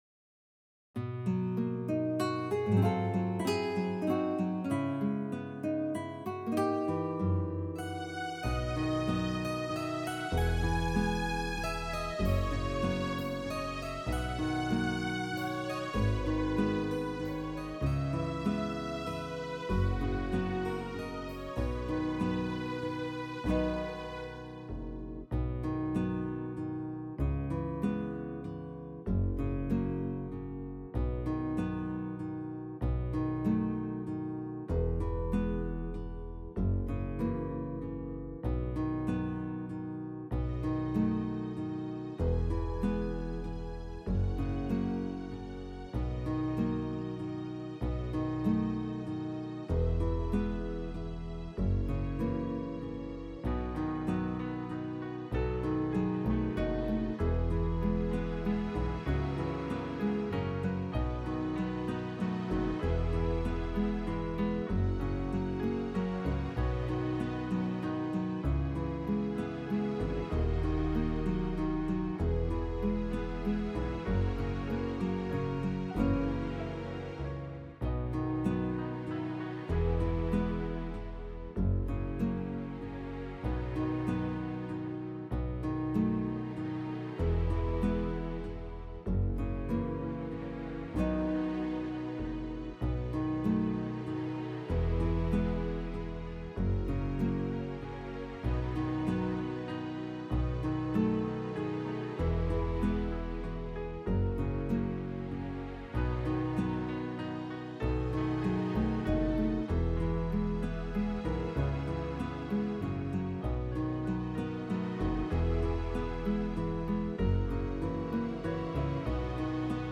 Tone Nam (B)
(Beat)